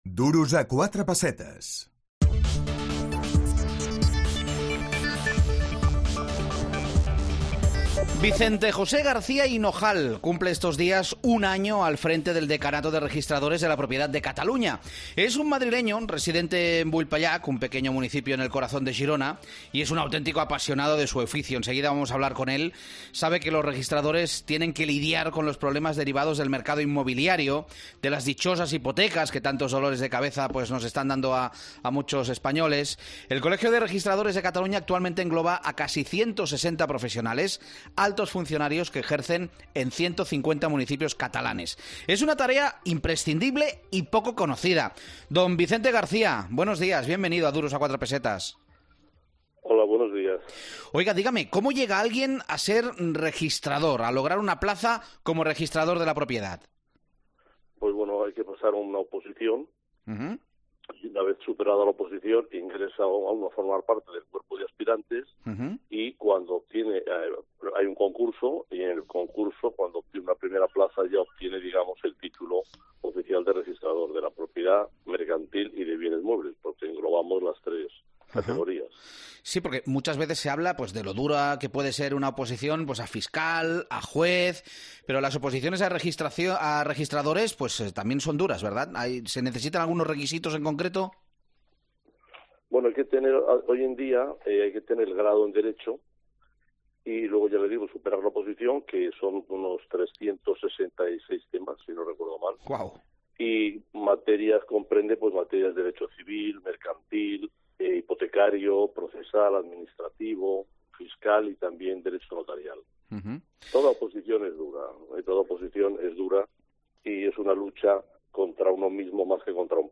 AUDIO: L'entrevistem quan fa un any al capdavant del decanat